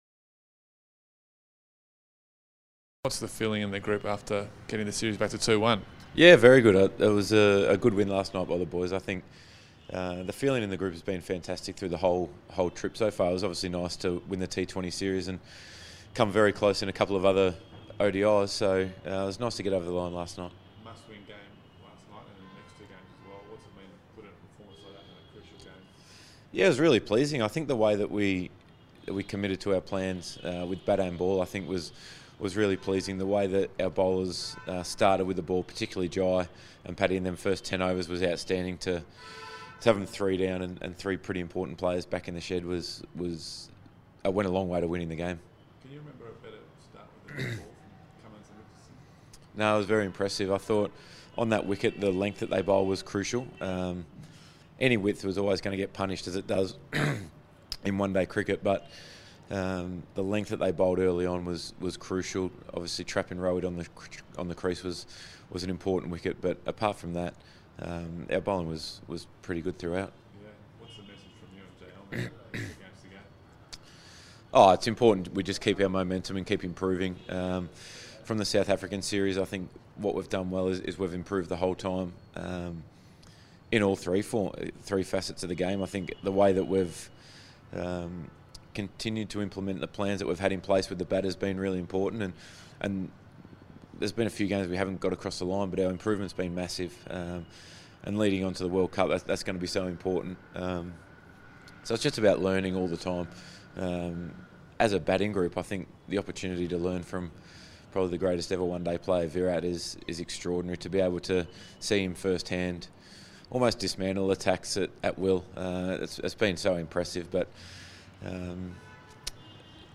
Aaron Finch Interview speaking to the media ahead of the fourth ODI
Australian Captain, Aaron Finch, spoke to the media ahead of the fourth ODI of the Qantas Tour of India.